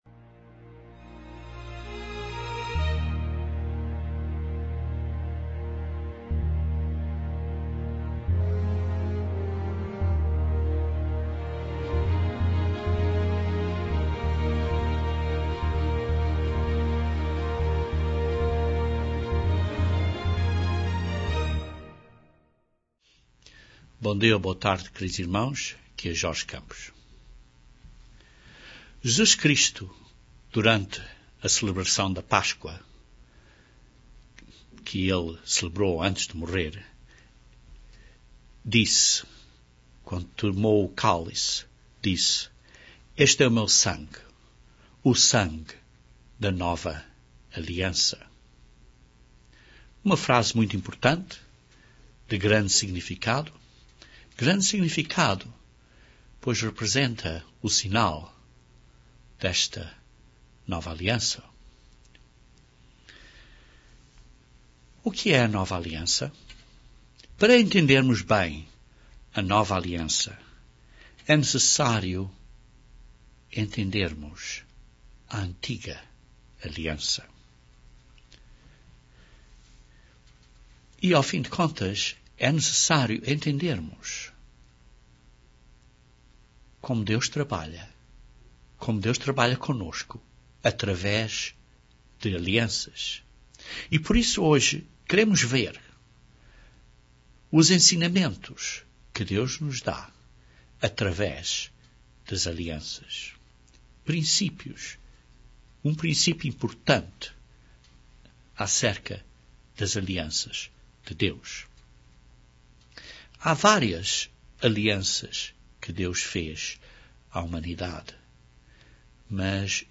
Para entendermos melhor a Nova Aliança, precisamos ter um melhor entendimento da estrutura para a salvação que corre pelas Alianças que Deus fez com Seu povo através das eras. Este sermão é o primeiro de três sermões acerca do ensinamento das Alianças.